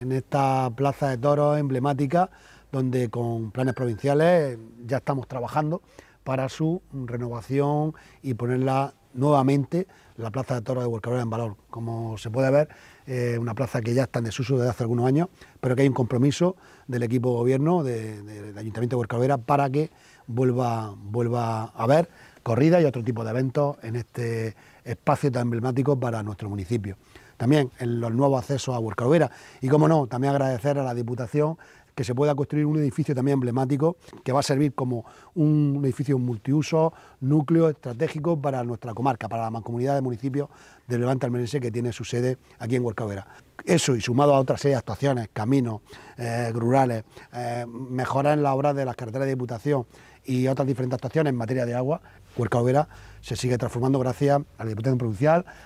08-01_plaza_huercal_overa_alcalde.mp3